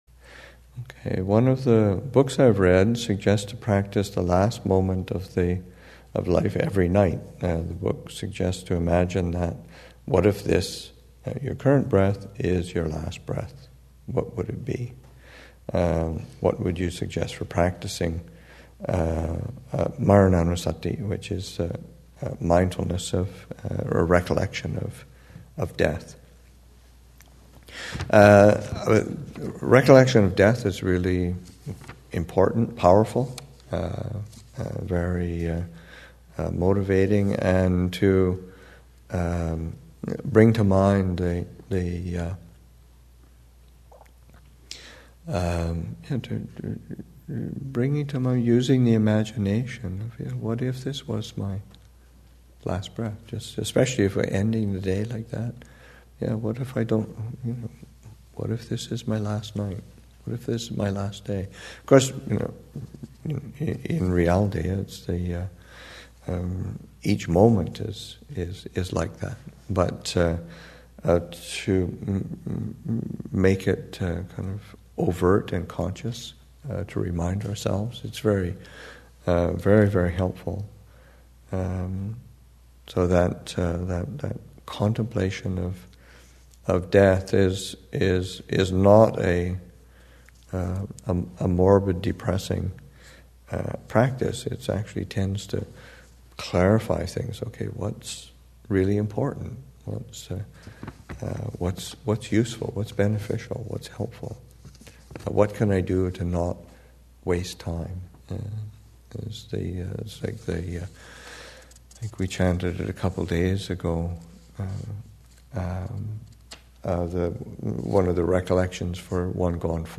2015 Thanksgiving Monastic Retreat, Session 4, Excerpt 14